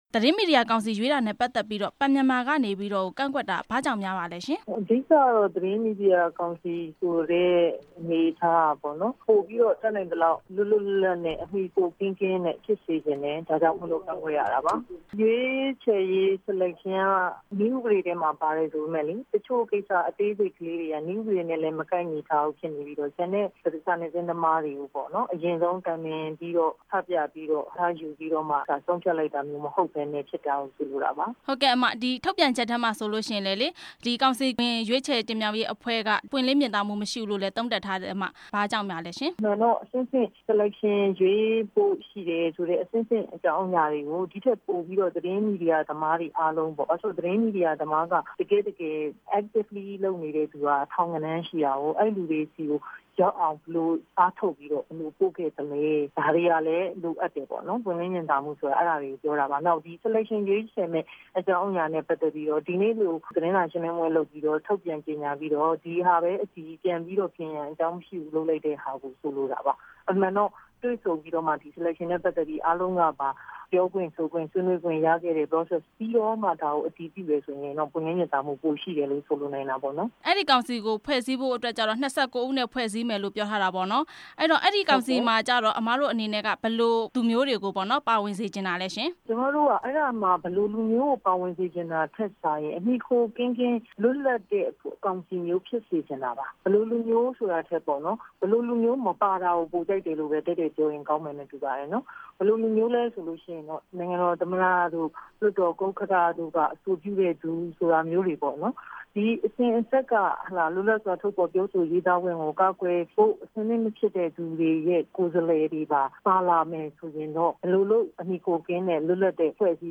မသီတာ(စမ်းချောင်း) ကို မေးမြန်းချက်